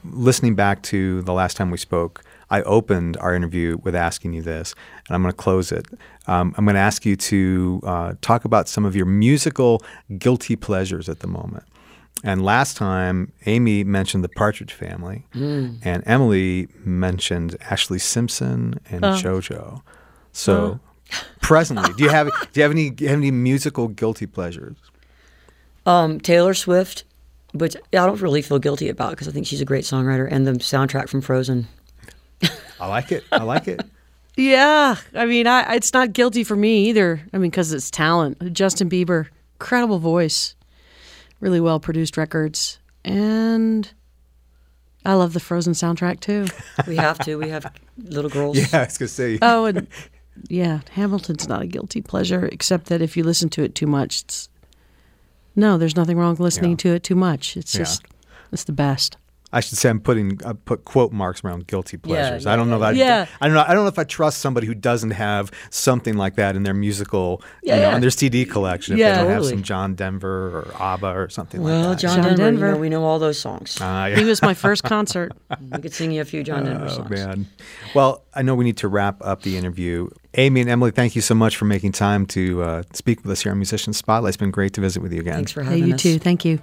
(recorded from webcast)
08. interview (1:26)